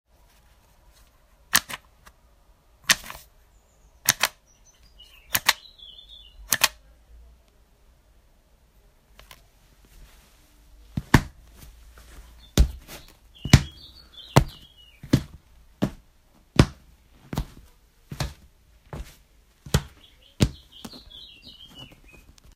stone_walk.ogg